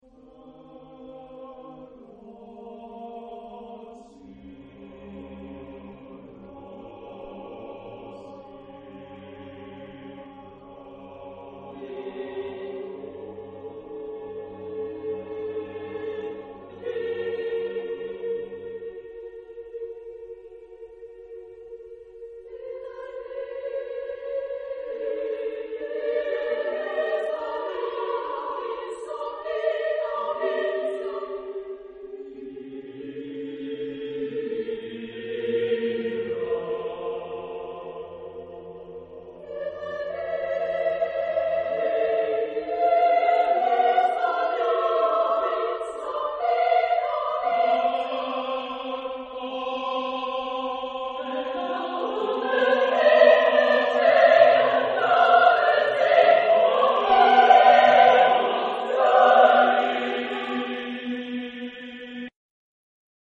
SSAATTBB mixed.
Opera chorus.